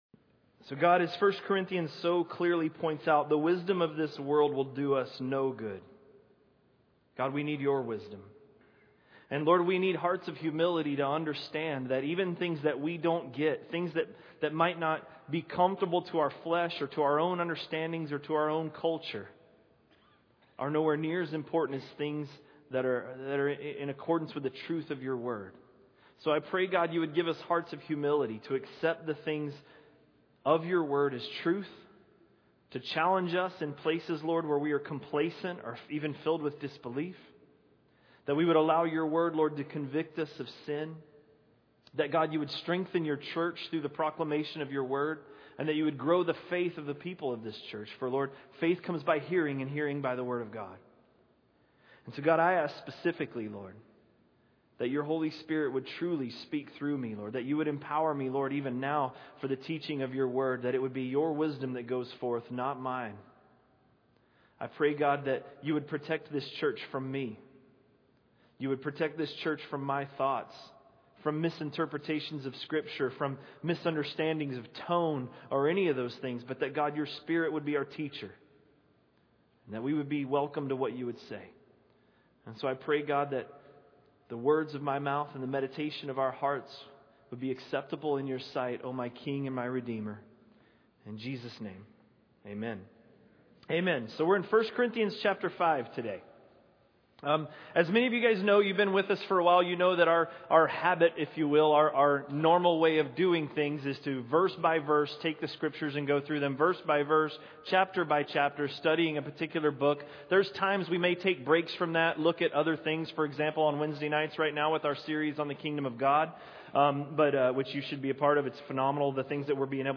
A message from the series "1 Corinthians." 1 Corinthians 5–5